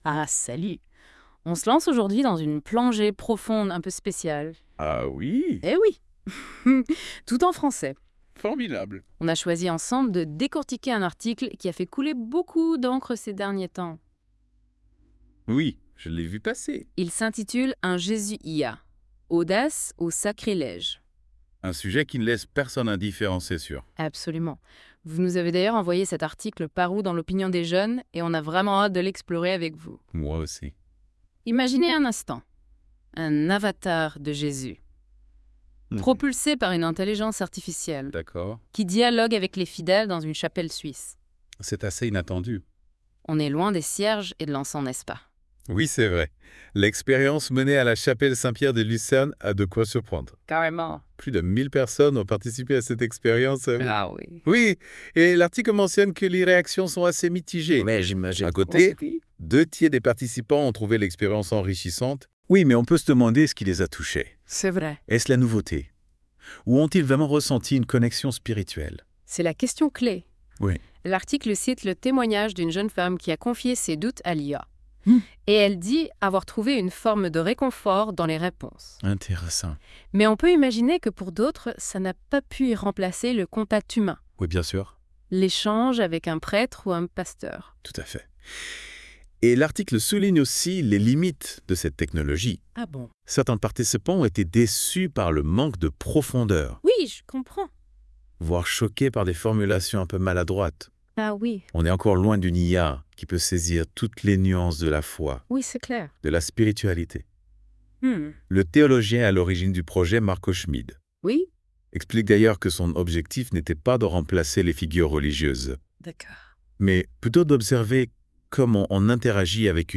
Débat podcast Un Jésus IA audace ou sacrilège.wav (22.54 Mo)